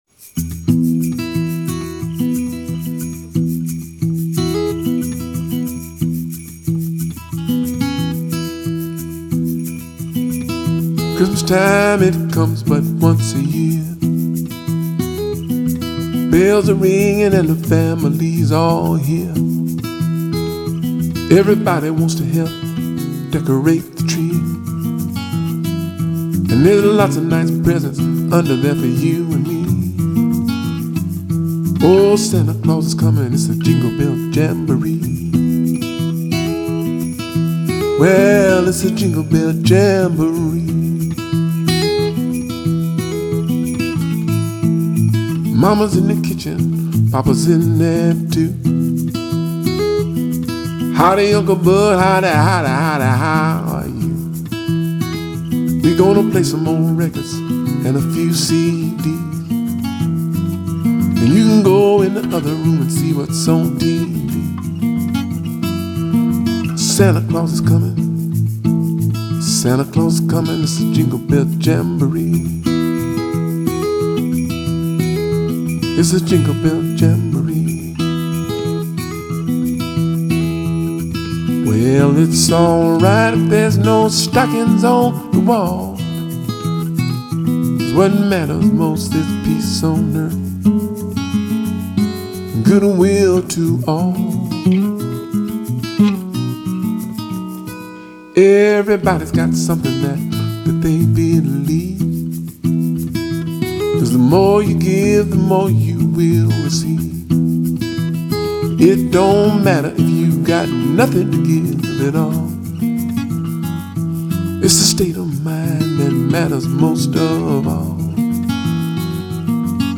Жанр: Contemporary Blues